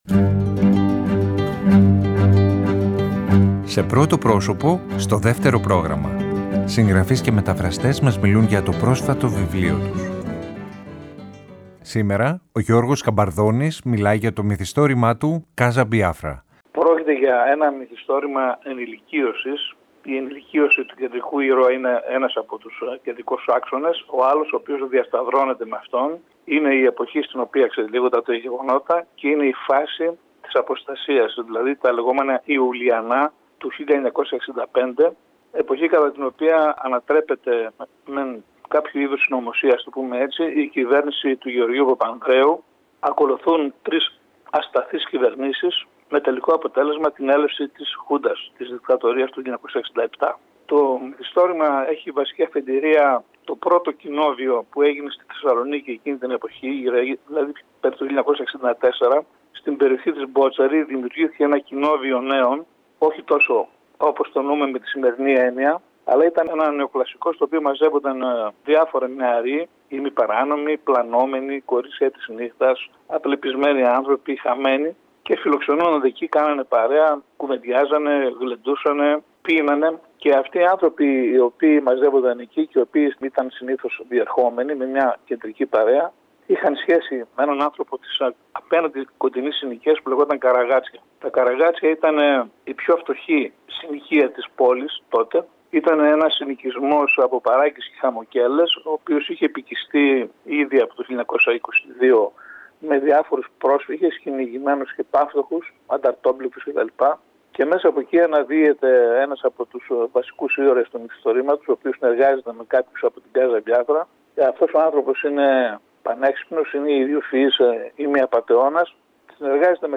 Συγγραφείς και μεταφραστές μιλάνε
Σήμερα, Δευτέρα 22 Μαρτίου ο  Γιώργος Σκαμπαρδώνης μιλάει για το μυθιστόρημα του “Casa Μπιάφρα”.